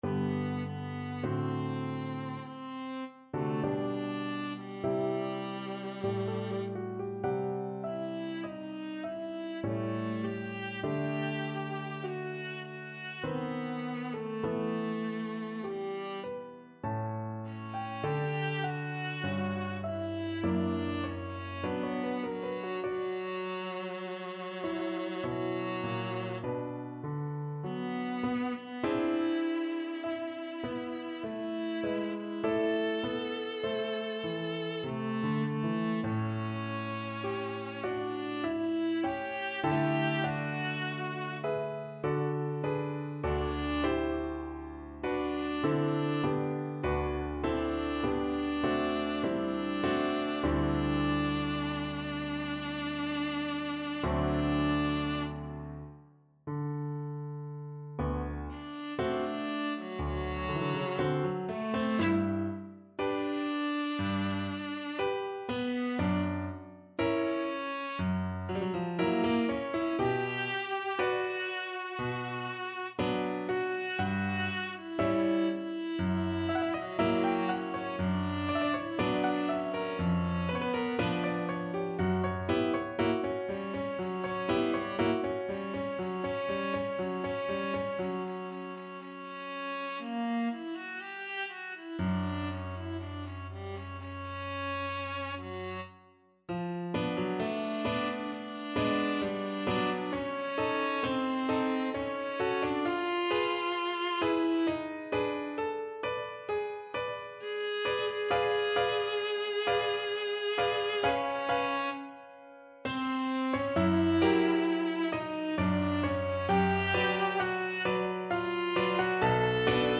Viola version
Cantabile (=50) Adagio molto
3/4 (View more 3/4 Music)
E4-B5
Classical (View more Classical Viola Music)